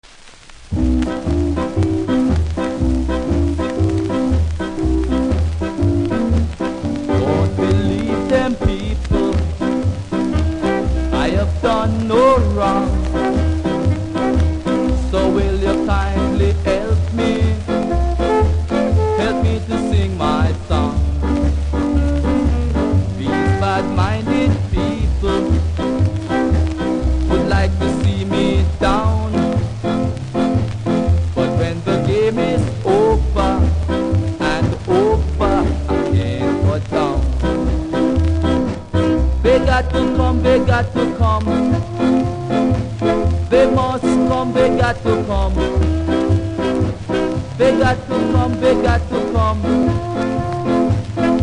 B SIDE : プレス起因のノイズありますので試聴で確認下さい。